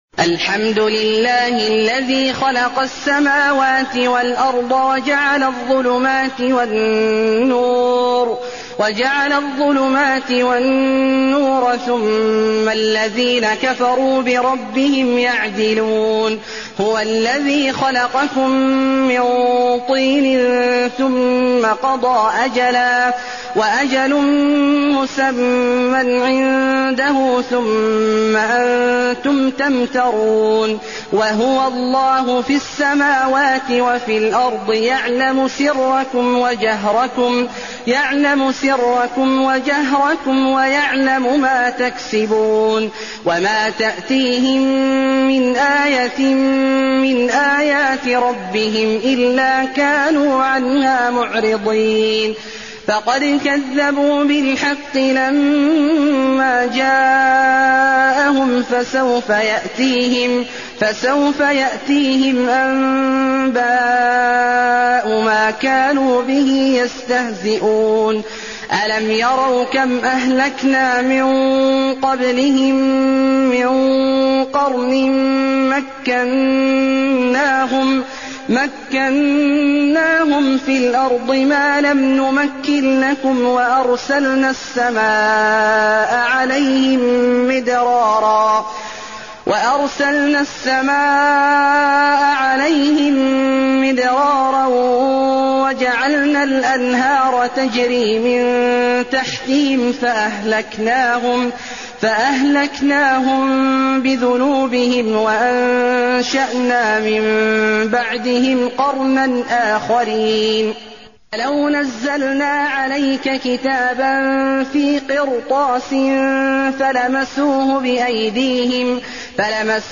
المكان: المسجد النبوي الأنعام